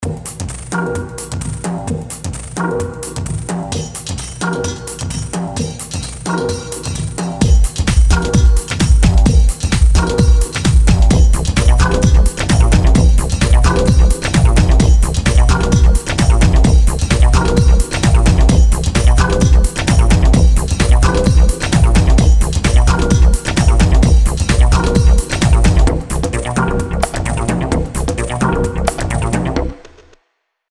full mix and include 60 & 30 edit version